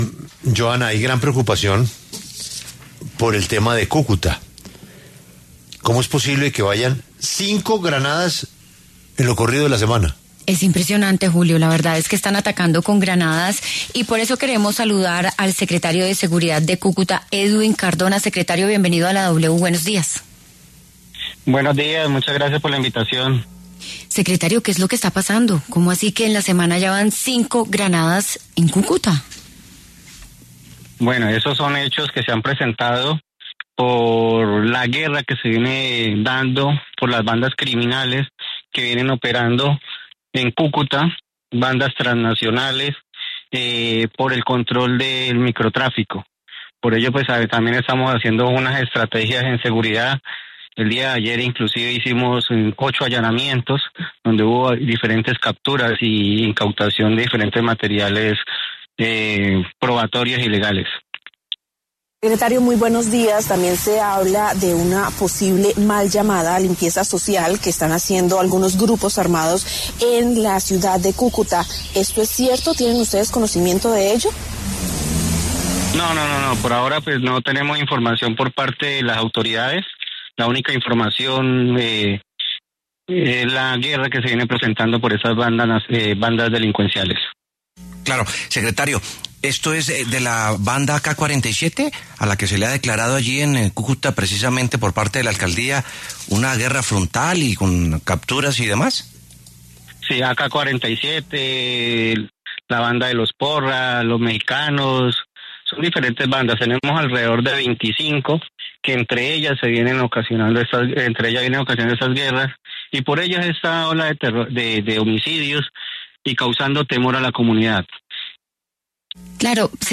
Edwin Cardona, secretario de seguridad de Cúcuta, se pronunció en La W sobre los atentados ocurridos en las comunas 6 y 7.